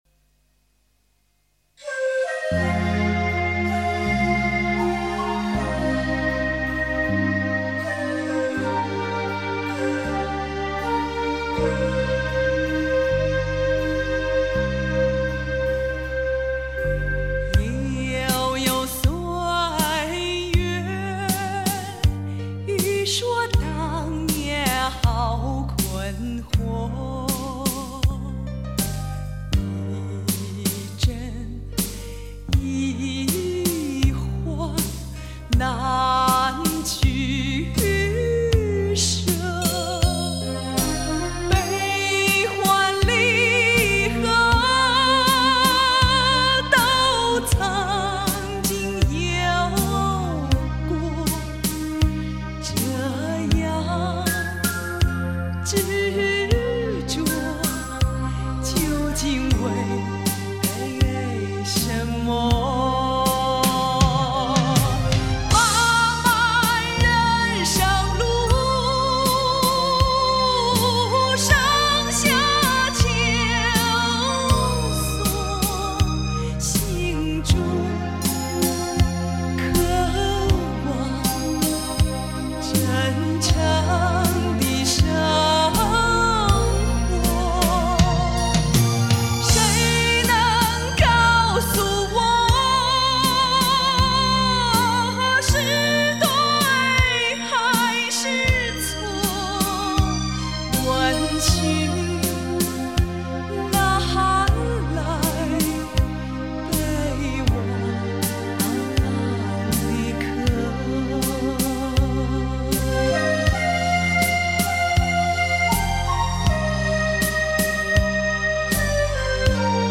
慢四舞曲